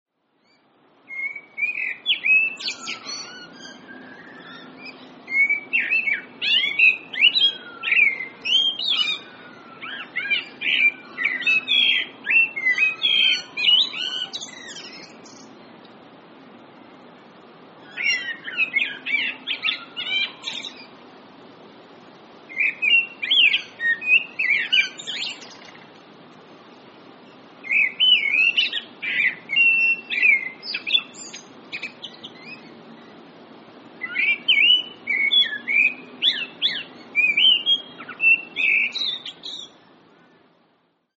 Common Blackbird -Turdus merula
Voice: pleasant, mellow song; 'pink-pink' call; high-pitched 'tseer'; harsh alarm call.
Call 1: part of a longer song sequence
Com_Blackbird_1_song.mp3